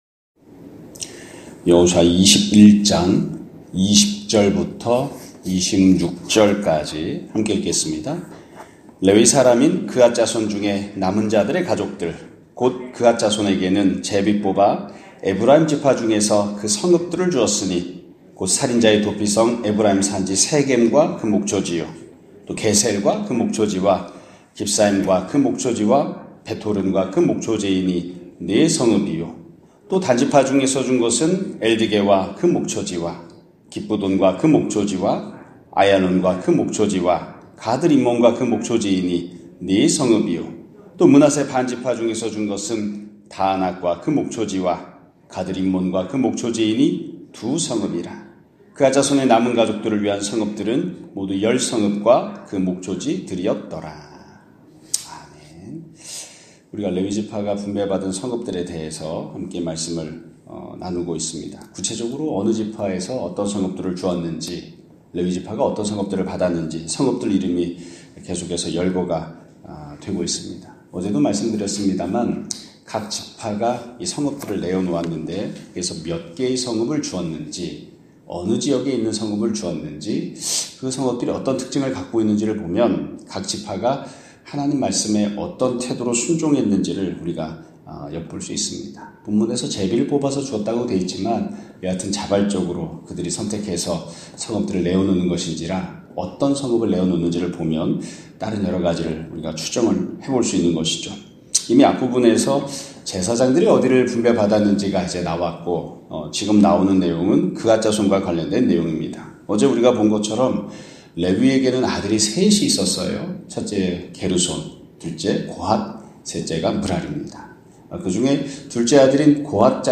2025년 1월 22일(수요일) <아침예배> 설교입니다.